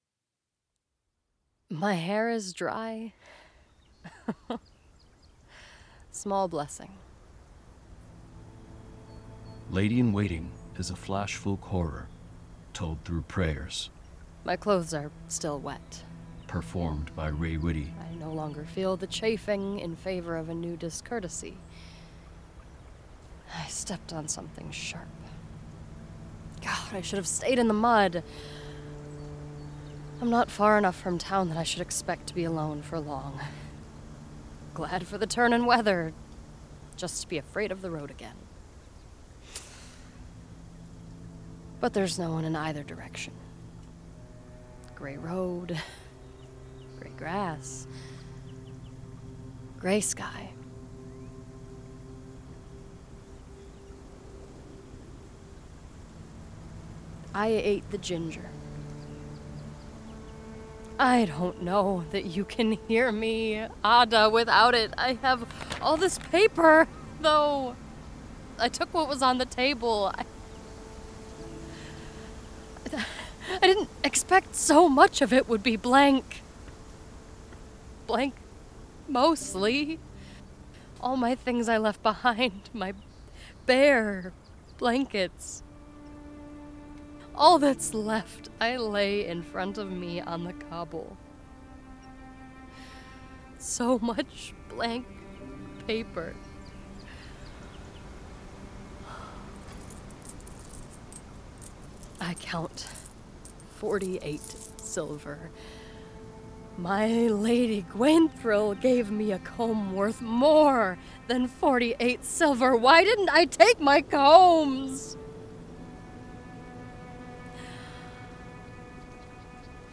This is an immersive audio drama. So, turn your volume up.